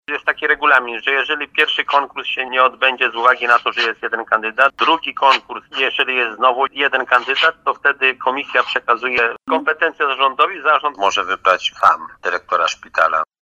To oznacza, że teraz decyzja należeć będzie do Zarządu Powiatu Stalowowolskiego. Mówi starosta Stalowowolski Janusz Zarzeczny